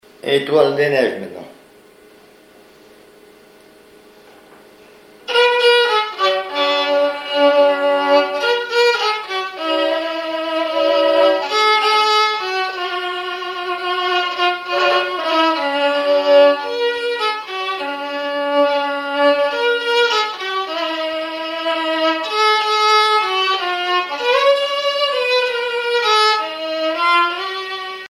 violoneux, violon,
danse : valse musette
Pièce musicale inédite